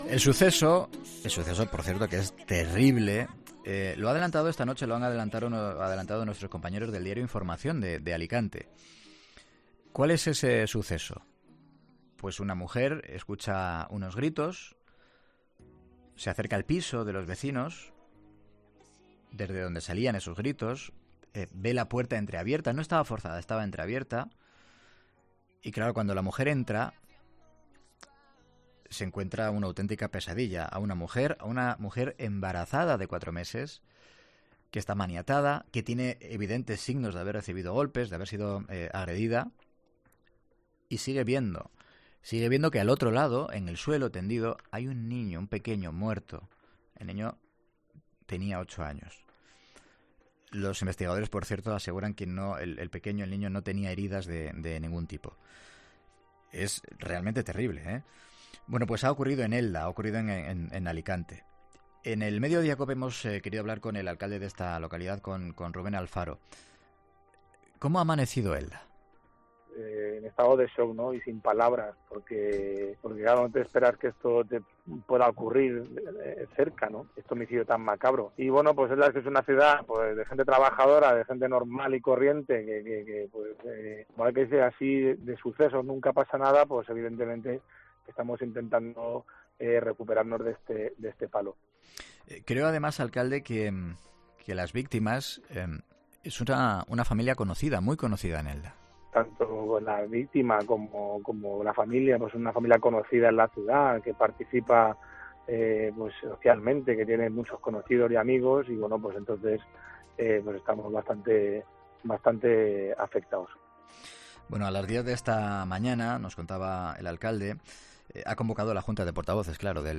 ESCUCHA LA ENTREVISTA COMPLETA | Rubén Alfaro en 'Mediodía COPE'